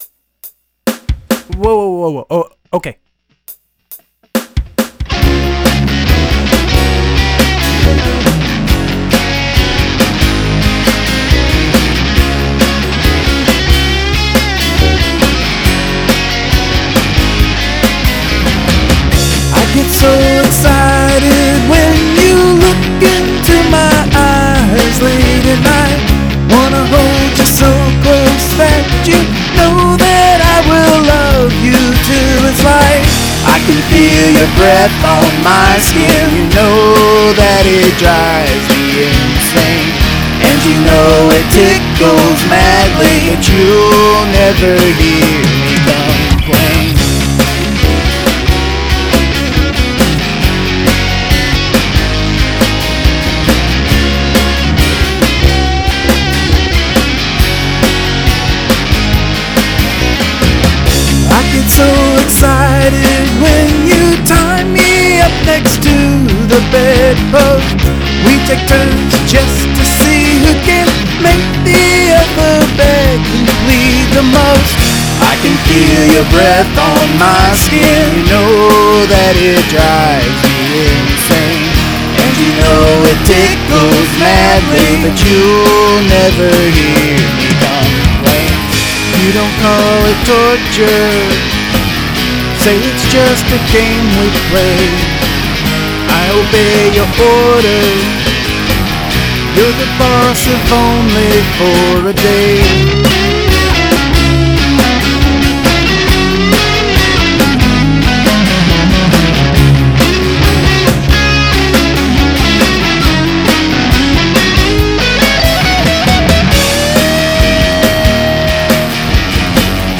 Recorded on the BR-900CD
Mixed with Reaper on a Windows machine
fine guitar playing. great original.